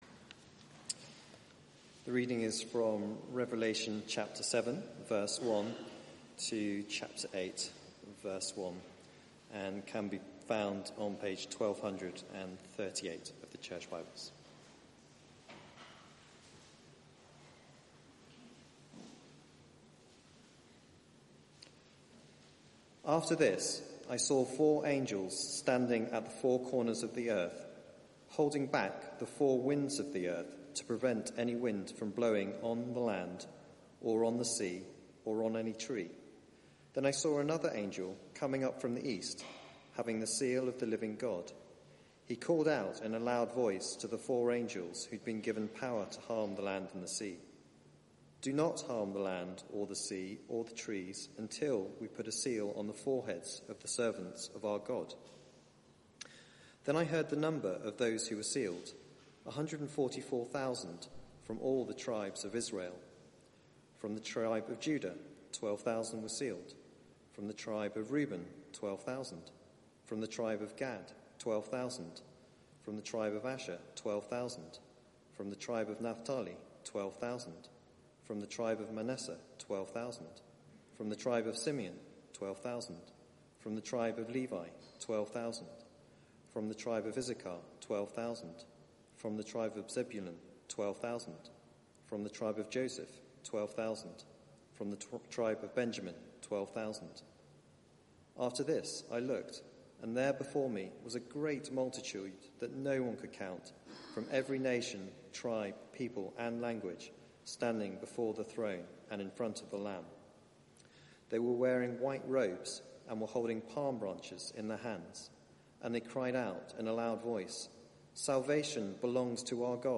Media for 4pm Service on Sun 23rd Jun 2019 16:00 Speaker
Series: The Lamb Wins Theme: Many nations, one Saviour Sermon Search the media library There are recordings here going back several years.